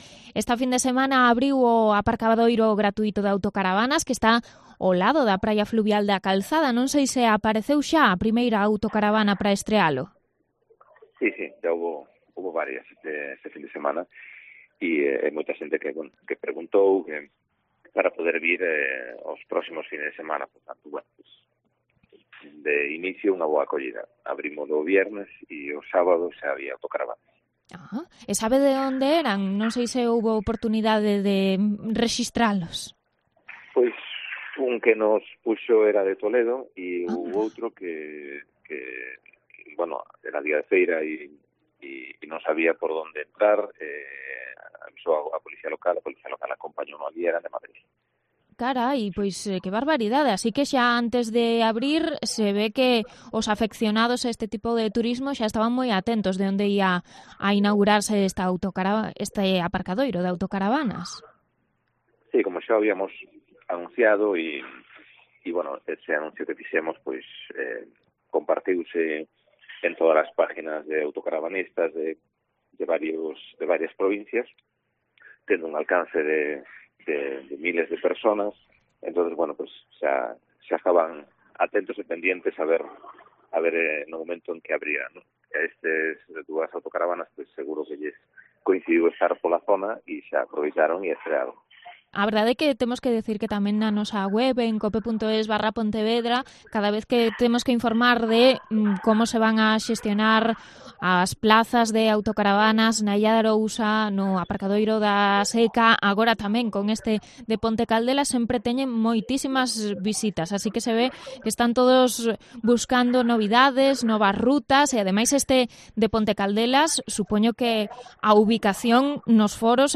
Entrevista al alcalde de Ponte Caldelas, Andrés Díaz, tras la apertura de un parking de autocaravanas